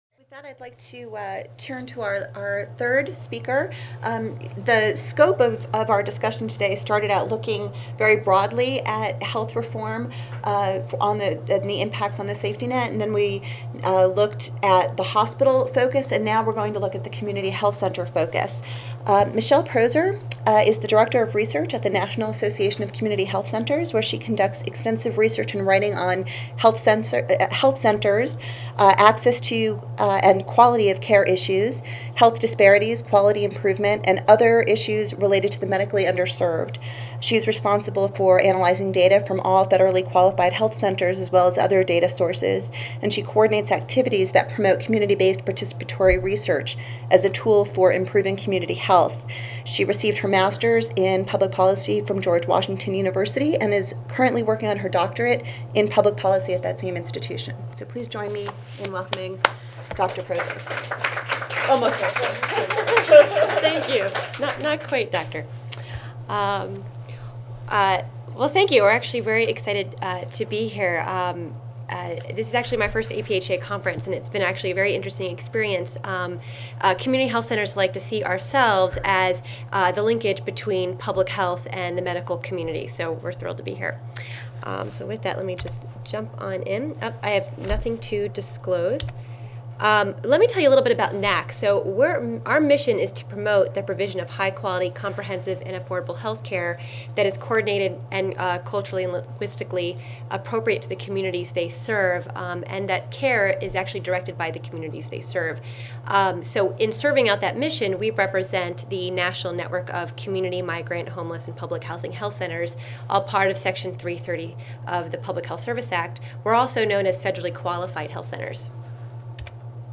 5110.0 Safety Net Providers & the Patient Protection and Affordable Care Act Wednesday, November 10, 2010: 10:30 AM - 12:00 PM Oral This workshop will examine the recent passage of Health Care Reform legislation and its affect on the health care safety net, including public hospitals and community health centers. Speakers will discuss how safety net institutions and the patient populations they serve will likely beimpacted by the new law.